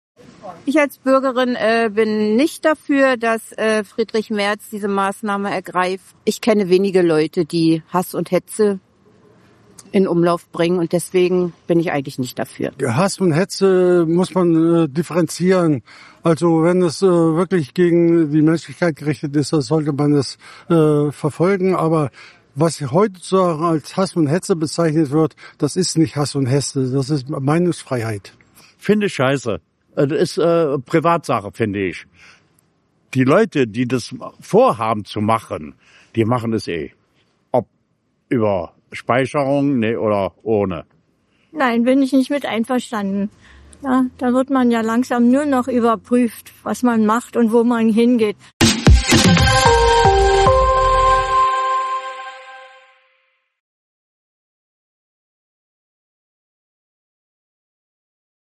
im Berliner Bezirk Buch